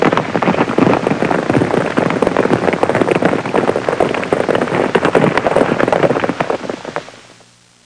סוסים דוהרים.mp3